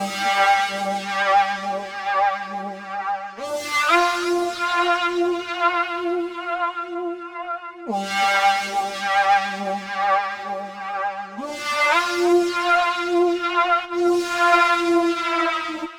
Session 08 - Retro Lead 02.wav